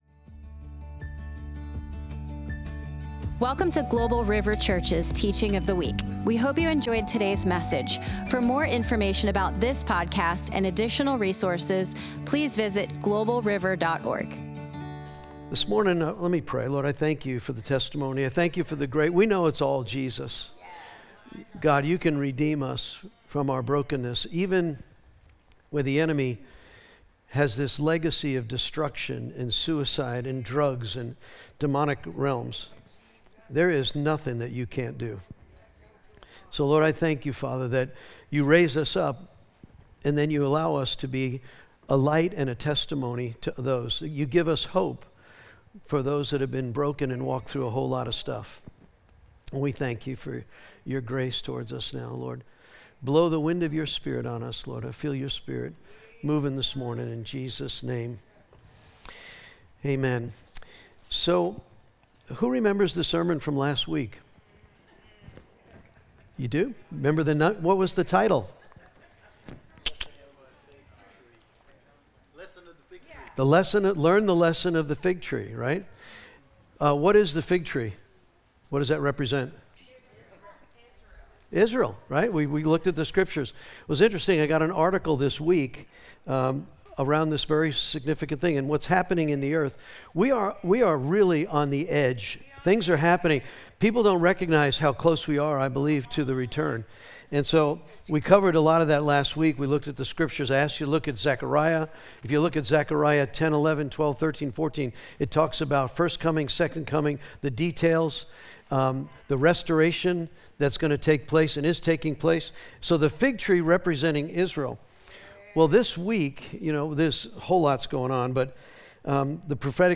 Walk As Children Of The Light - Sunday Morning Global River Message Of The Week podcast To give you the best possible experience, this site uses cookies.